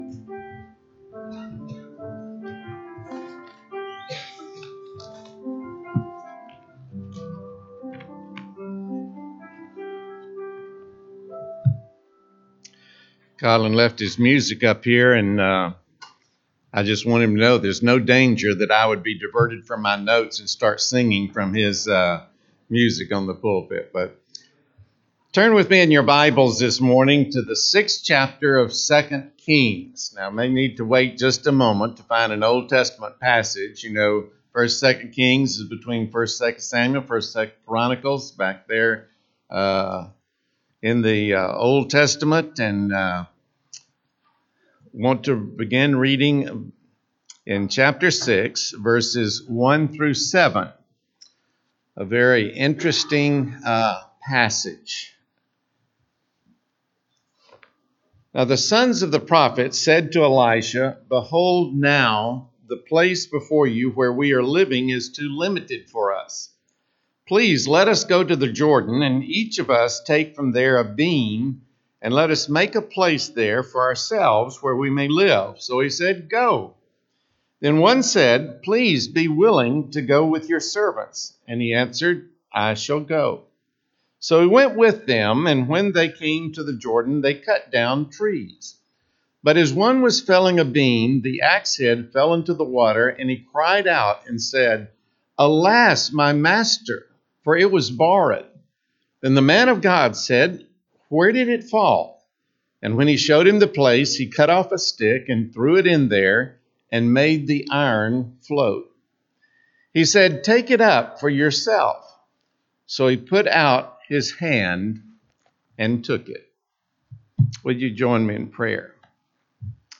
Audio Sermons: Videos of service can be seen on Facebook page - Trinity Baptist Church